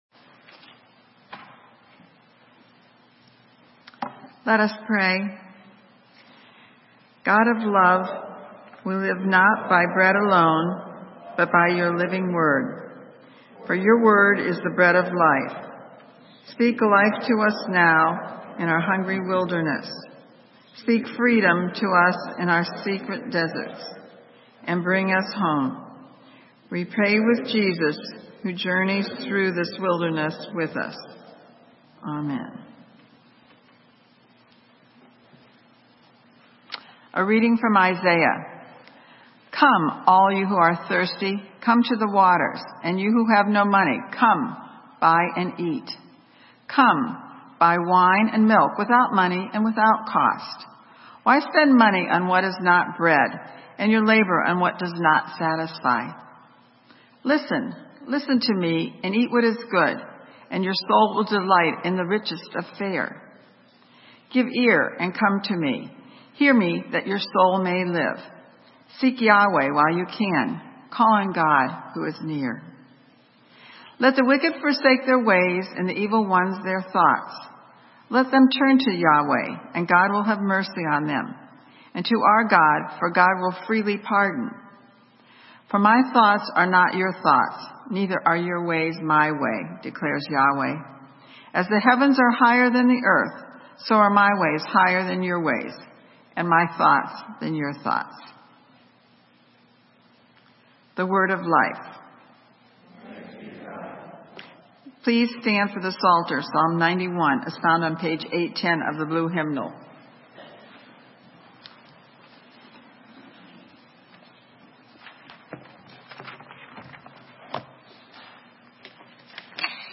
Sermon: Temptations - St. Matthew's UMC